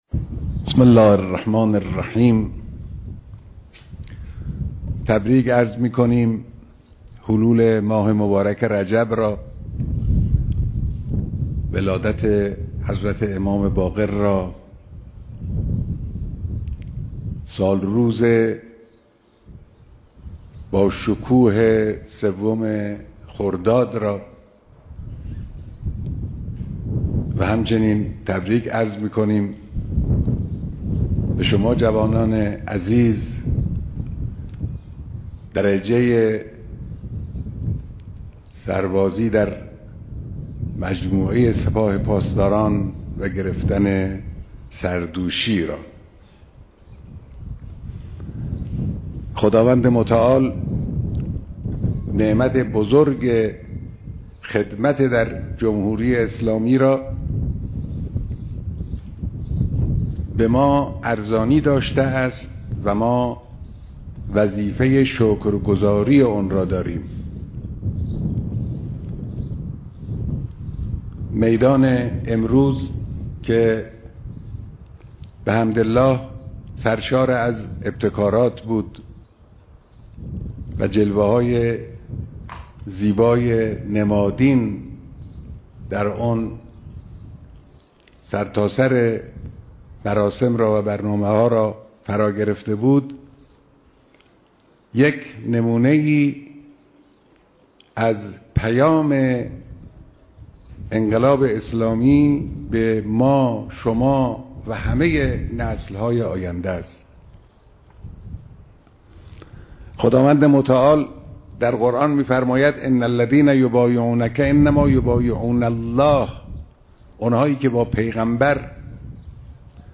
مراسم دانش آموختگی دانشجویان افسری دانشگاه امام حسین(ع)
بيانات در دانشگاه امام حسين عليه‌السلام